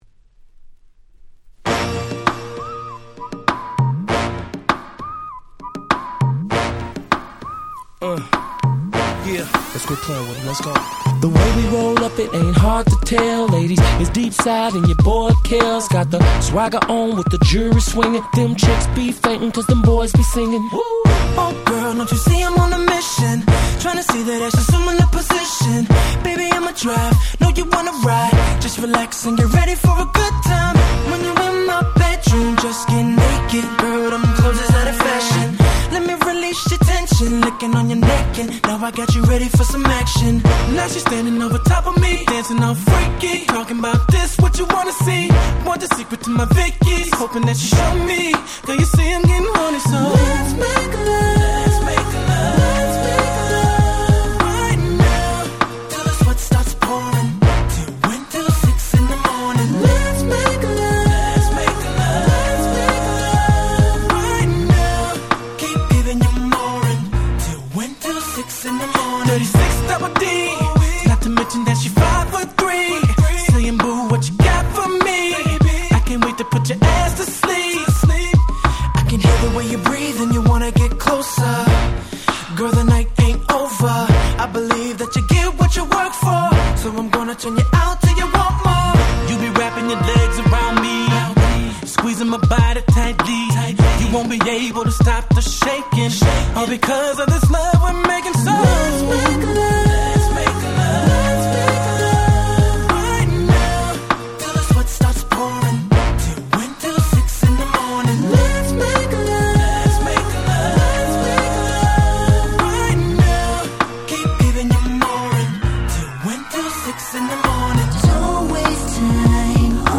06' Smash Hit R&B !!
2サビ後のブリッジ部分は超美メロでとろけます！